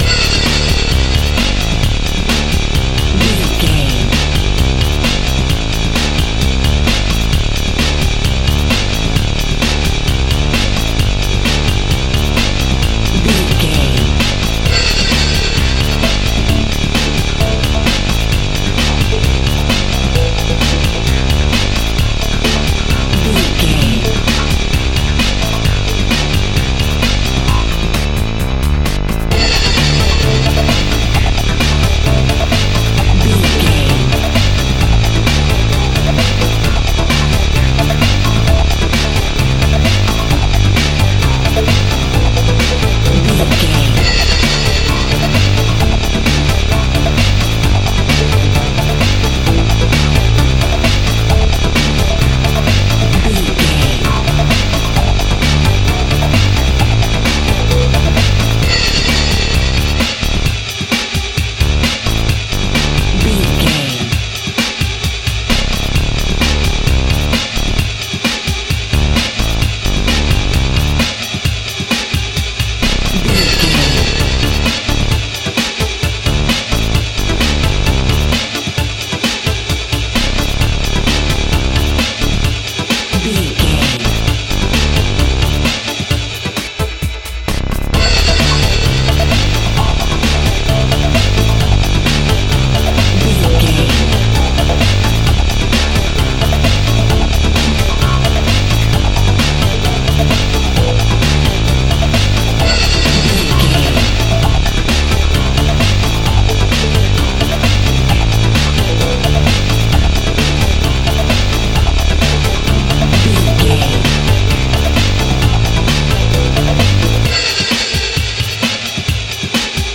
Epic / Action
Fast paced
Aeolian/Minor
energetic
driving
intense
futuristic
drum machine
synthesiser
breakbeat
pumped up rock
power pop rock
electronic drums
synth lead
synth bass